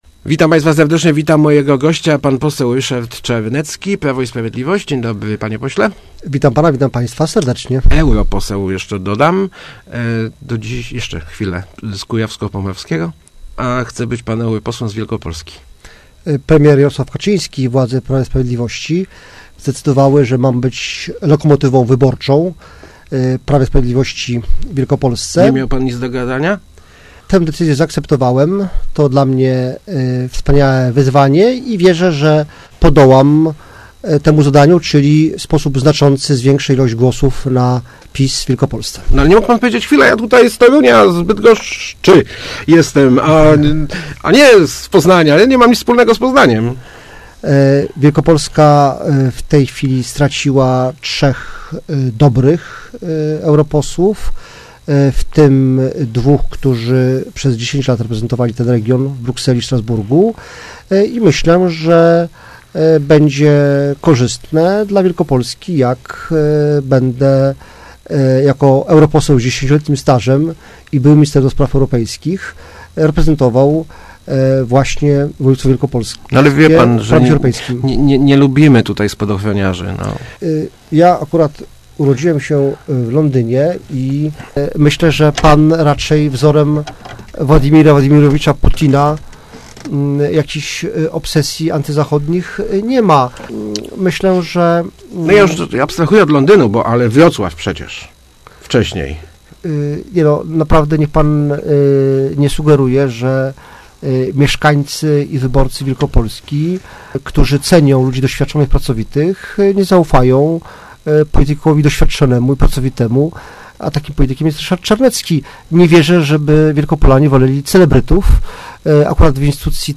Liczę na to, że mieszkańcy Wielkopolski, którzy cenią ludzi doświadczonych i pracowitych zaufają mi i wybiorą na swego przedstawiciela w Brukseli – mówił w Rozmowach Elki Ryszard Czarnecki, kandydat PiS do Europarlamentu.